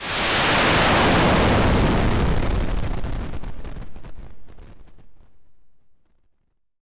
missile_launch.wav